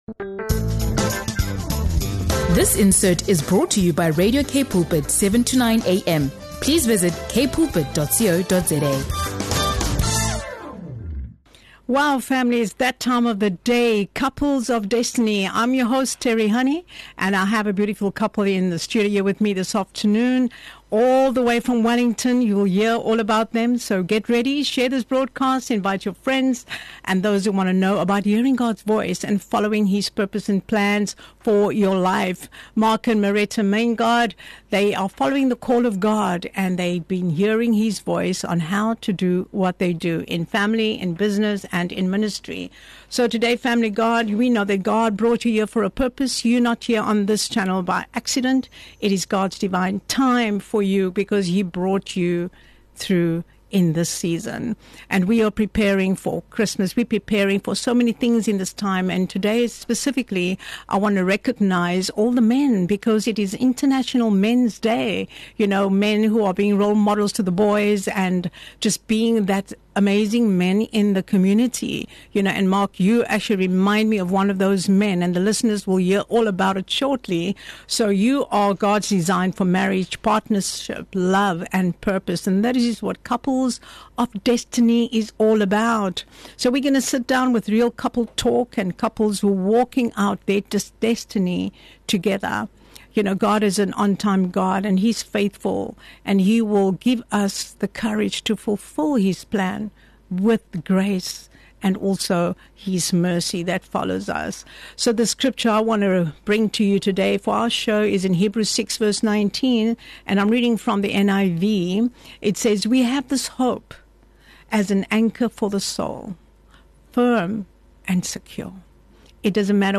This is a heartfelt conversation on marriage, purpose, community impact, and what it truly means to say “yes” to God—even when the assignment seems impossible.